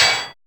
PAIN PERC.wav